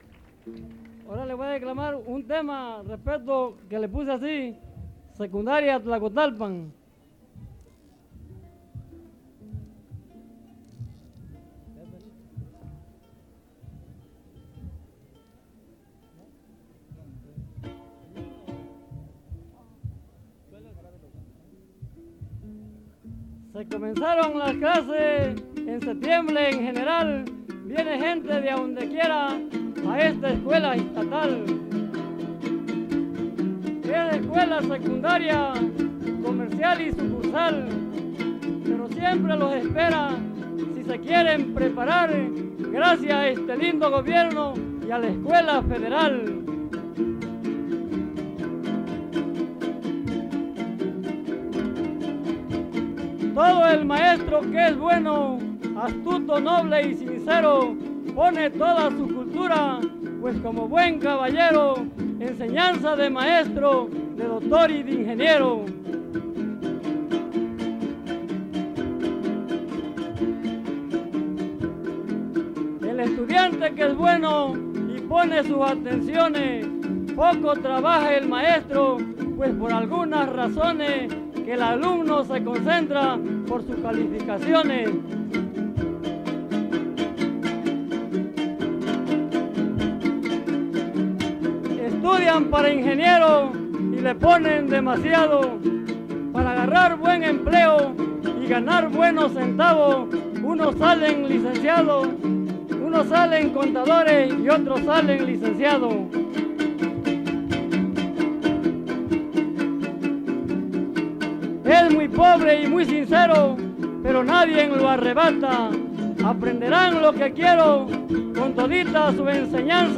• Los campesinos (Grupo musical)
Encuentro de jaraneros